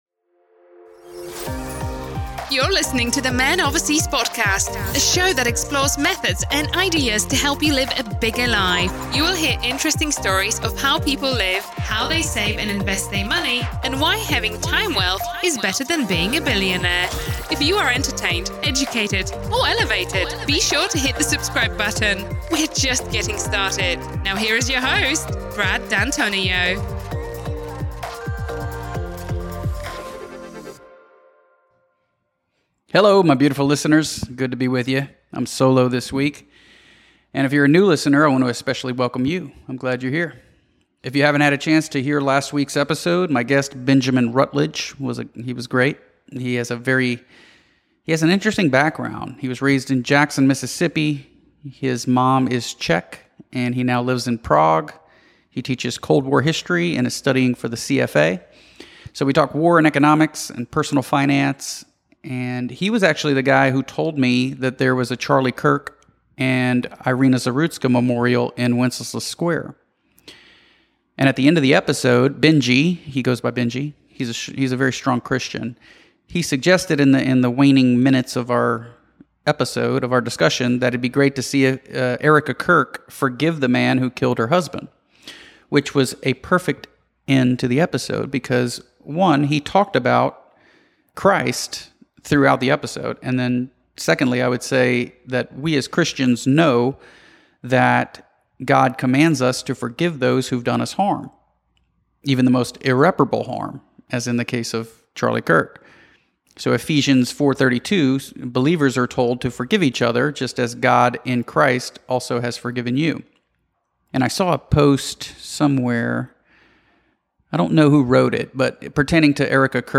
Coming to you solo from Houston this week.